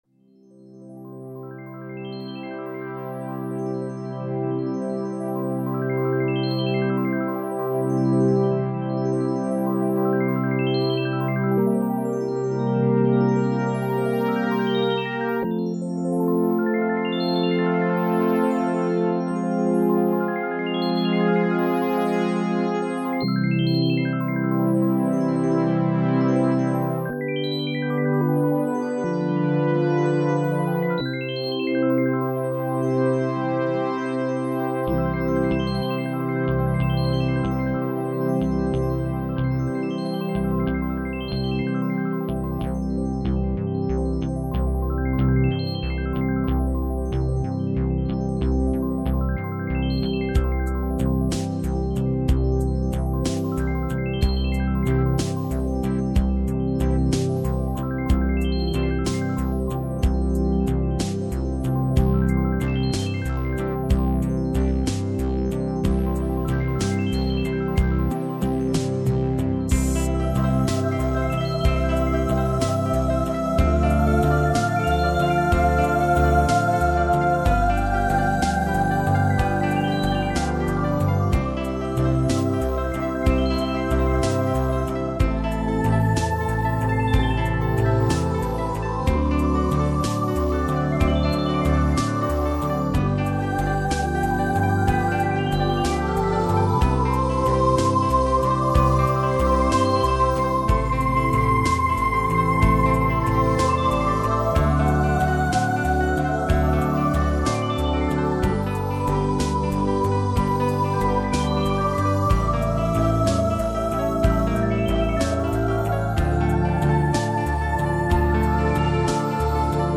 Keyboard und Synthesizer-Klassik
Keyboard und Synthesizer